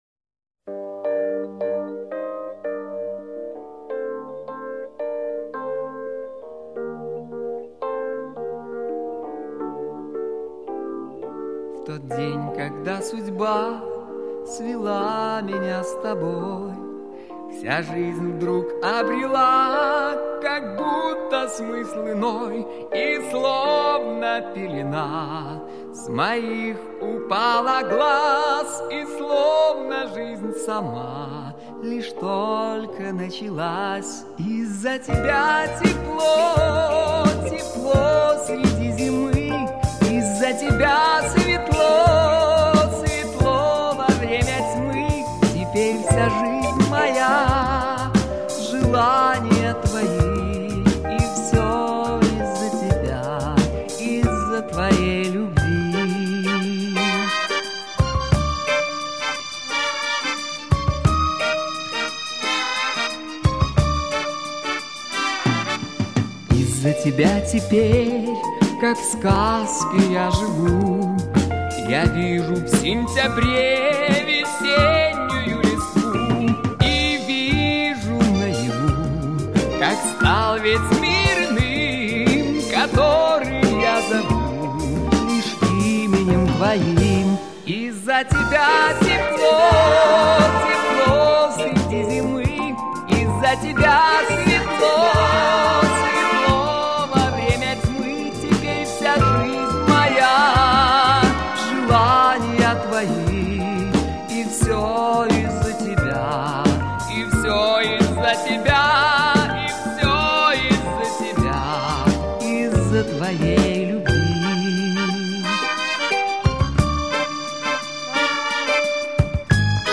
Всё с первого дубля - и запевы, и бэк - вокал.